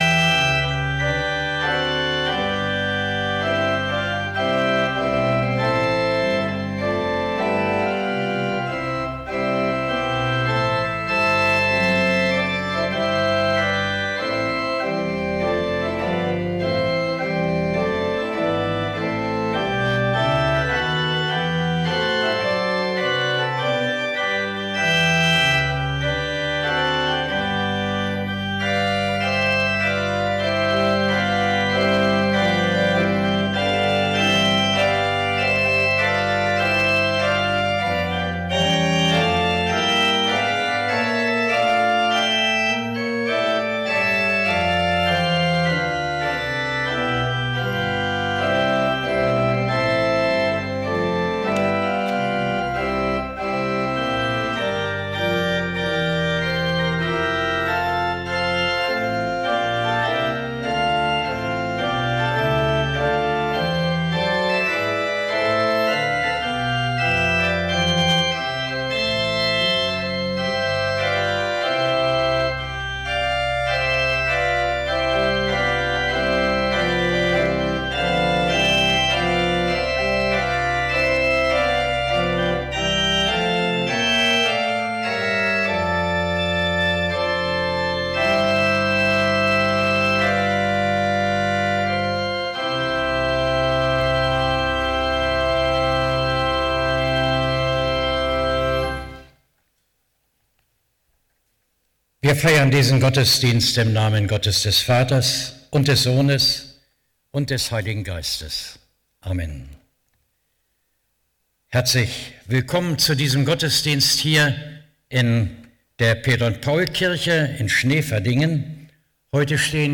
Gottesdienst - 06.07.2025 ~ Peter und Paul Gottesdienst-Podcast Podcast